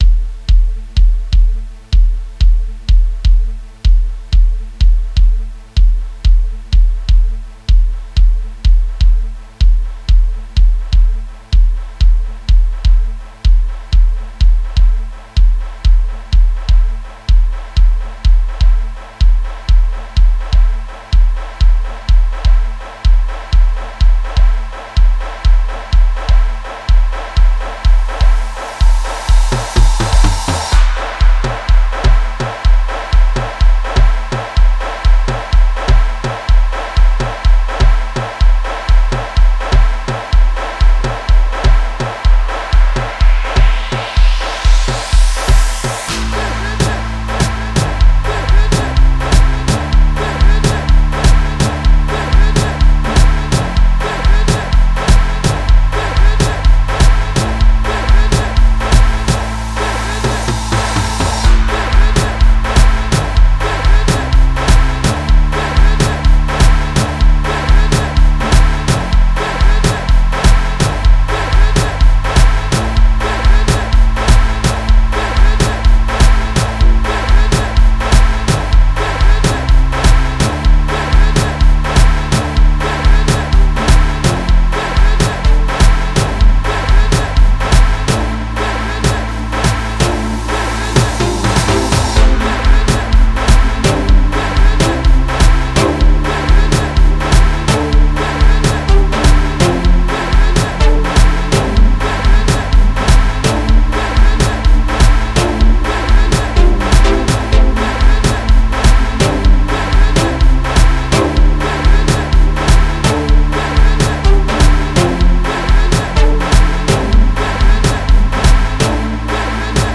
05:42 Genre : Gqom Size